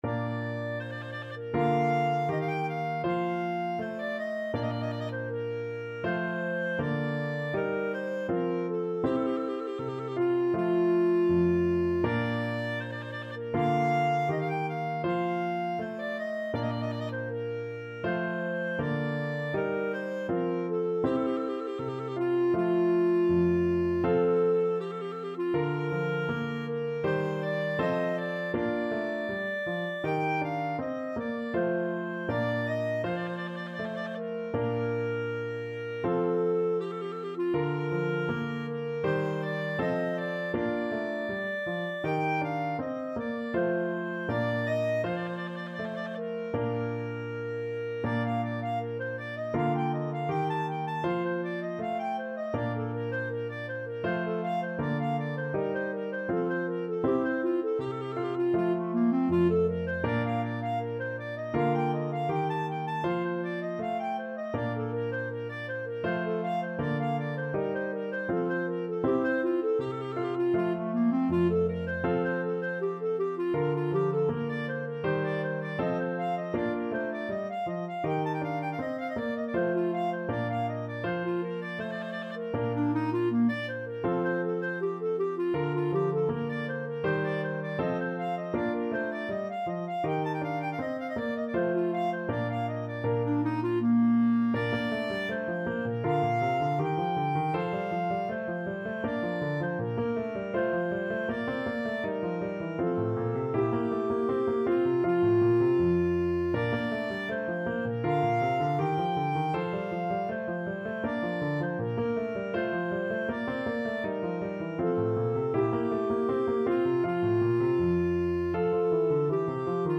Classical
4/4 (View more 4/4 Music)
Allegretto =80